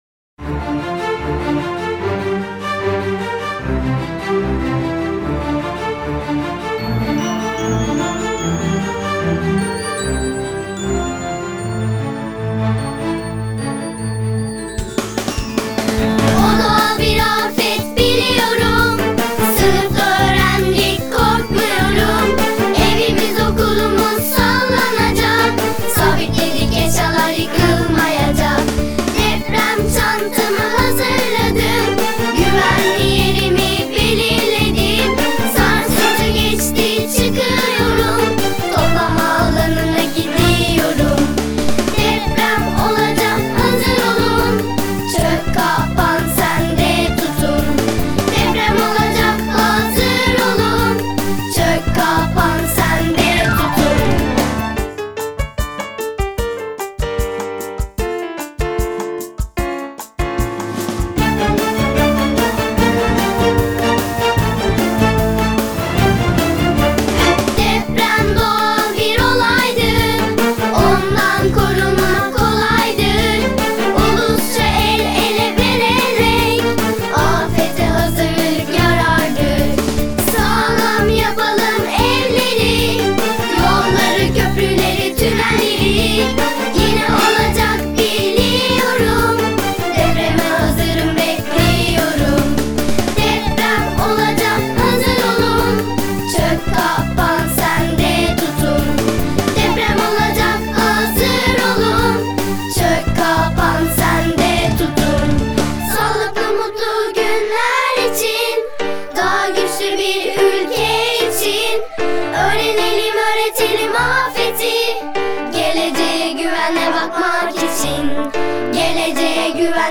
Çocuk Şarkıları